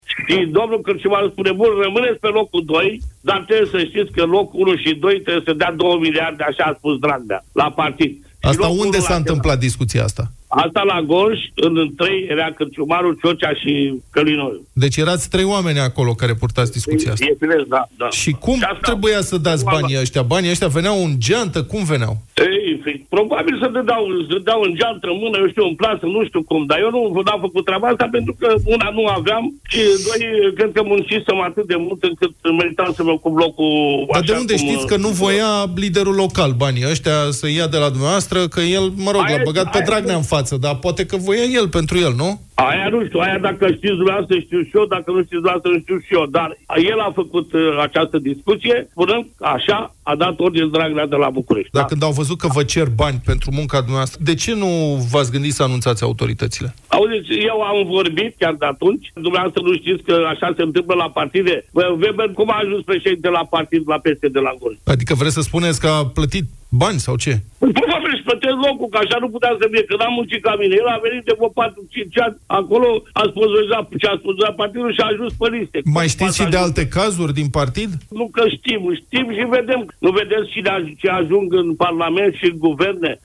Banii i-ar fi cerut liderul local al PSD, a povestit primarul din Bustuchin în această dimineață în emisiunea Deșteptarea.
• primarul Ion Ciocea din Bustuchin în Deșteptarea